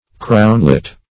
crownlet - definition of crownlet - synonyms, pronunciation, spelling from Free Dictionary Search Result for " crownlet" : The Collaborative International Dictionary of English v.0.48: Crownlet \Crown"let\ (-l?t), n. A coronet.